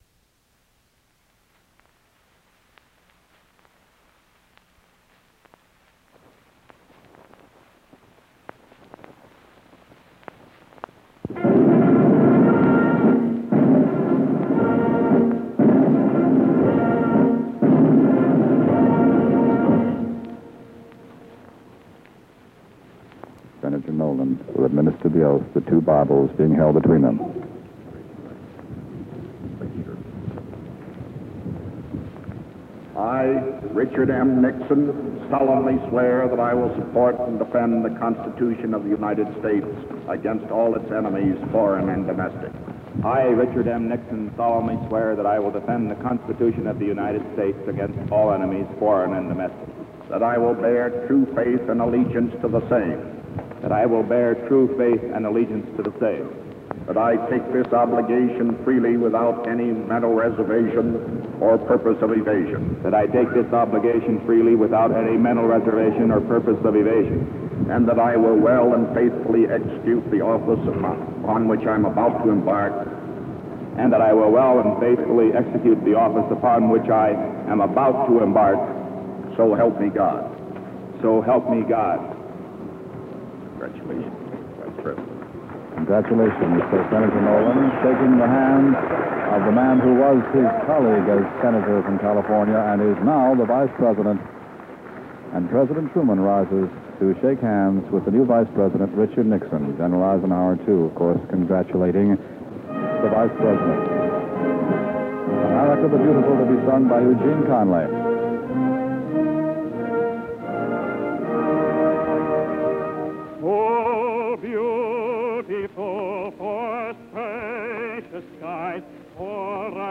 Eisenhower challenges citizens to help lead the world towards a future of freedom by making peace a way of life. He stresses the interdependence of the world, especially in economics, through nine principles he introduces to shape U.S. world leadership. The audio recording begins with Richard Nixon taking the vice presidential oath of office followed by te singing of 'America the Beautiful' and a prayer. President Eisenhower begins his speech at 9:45 on the recording.